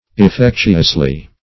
effectuously - definition of effectuously - synonyms, pronunciation, spelling from Free Dictionary Search Result for " effectuously" : The Collaborative International Dictionary of English v.0.48: Effectuously \Ef*fec"tu*ous*ly\, adv.